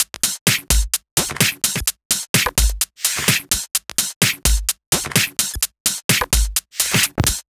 Index of /VEE/VEE Electro Loops 128 BPM
VEE Electro Loop 413.wav